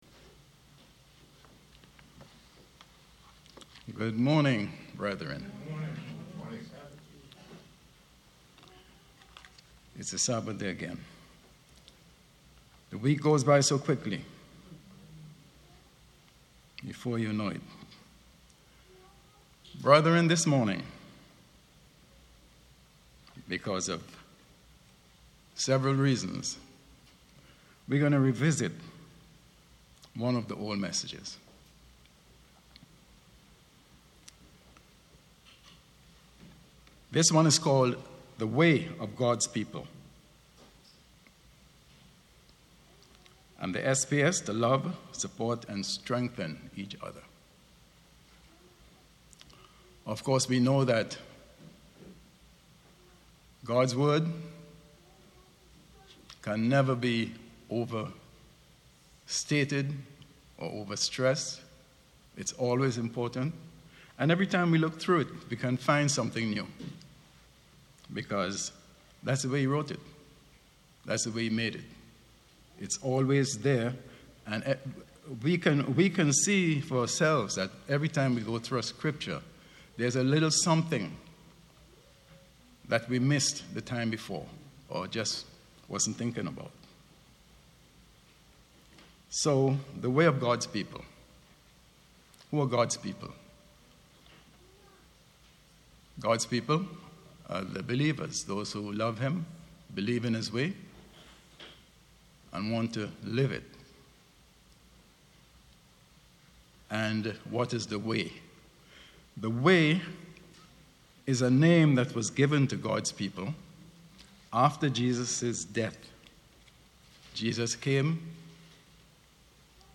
Sermons
Given in New Jersey - North New York City, NY